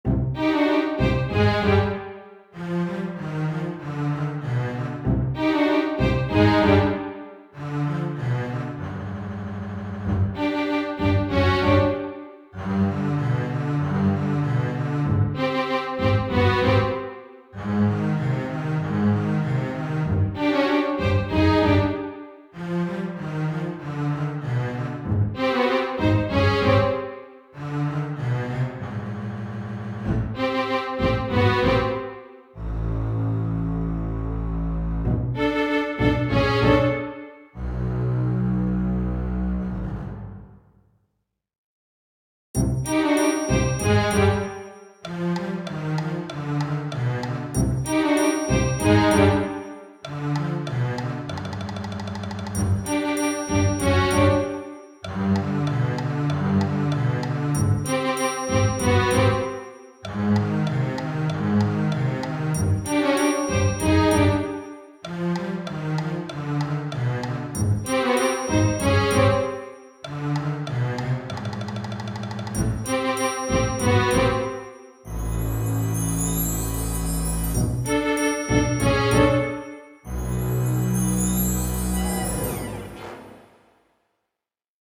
ogg(R) シュール オーケストラ
おかしな場面でオーケストラが欲しい時に。